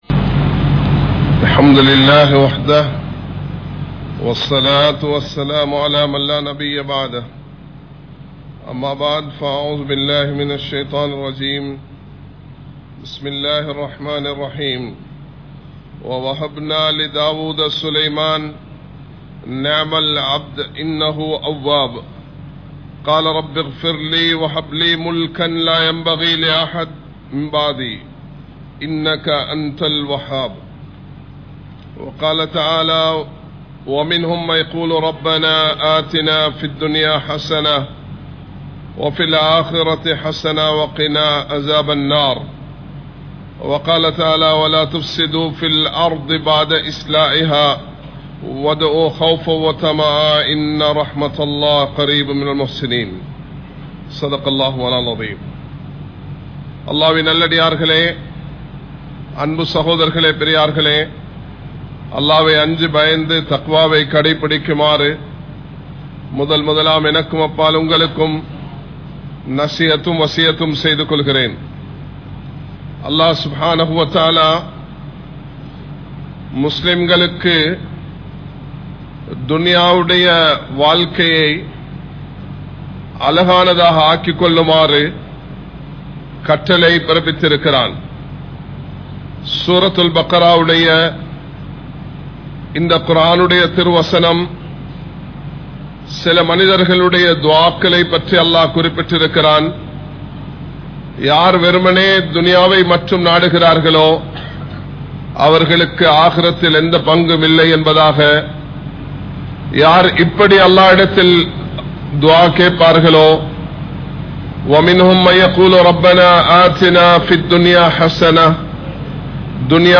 Aniyaayam Seium Aatsiyaalarhal Alinthu Poavaarhal (அநியாயம் செய்யும் ஆட்சியாளர்கள் அழிந்து போவார்கள்) | Audio Bayans | All Ceylon Muslim Youth Community | Addalaichenai
Kollupitty Jumua Masjith